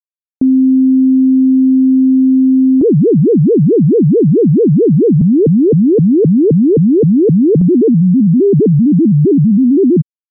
なので実際にOSCILLATOR1に対してLFOをかけ、ピッチを変化させてみました。
●普通に1小節のサイン波→LFO（サイン波）→LFO（鋸波）→LFO（ノイズ波）のサンプル